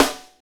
SNR FNK S0BR.wav